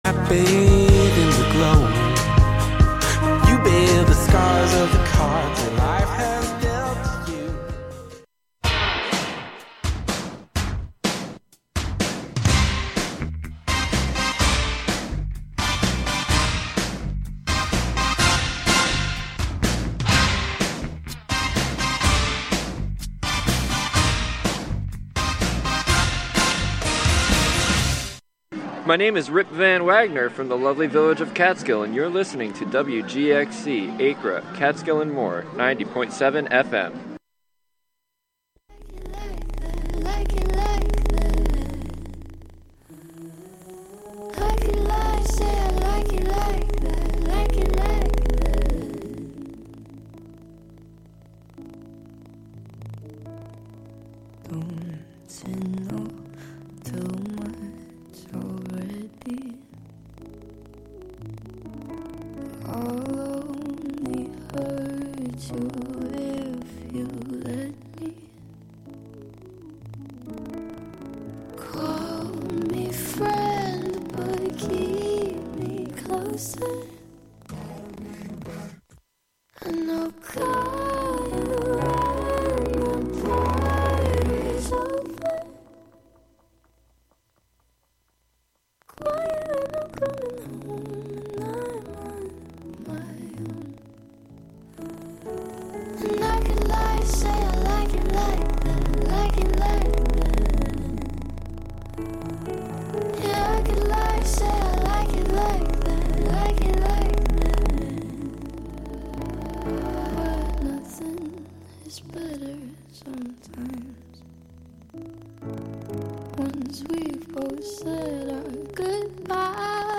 Our container: Radiolab, an open, experimental, youth-led programming and recording space. Get yr weekly dose of music appreciation, wordsmithing, and community journalism filtered through the minds and voices of the Youth Clubhouses of Columbia-Greene, broadcasting live out of the Catskill Clubhouse on Fridays at 6 p.m. and rebroadcast Sundays at 7 a.m.